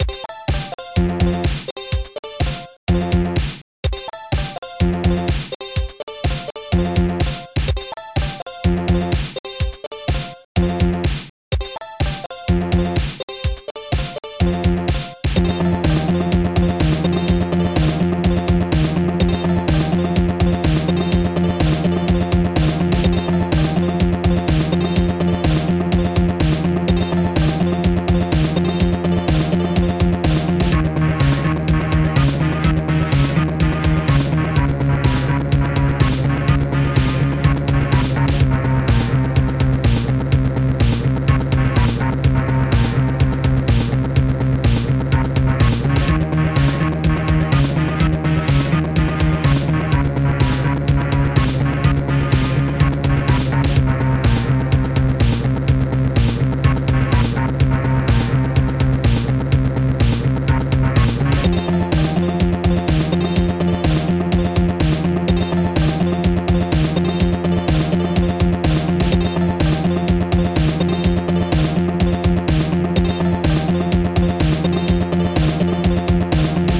SoundTracker Module
synth15 synth10 bassdrum8 snaredrum10 synth13